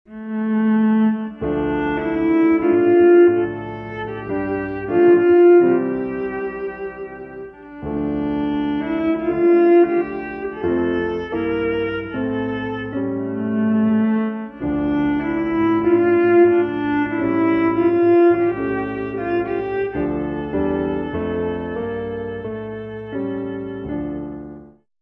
a traditional 16th century English song